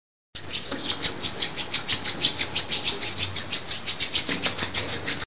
Frote de tejido
Me gusta Descripción Grabación sonora que capta el sonido de la acción del frotar entre tejidos (fricción de una parte del tejido frotándose contra otra). Sonidos cotidianos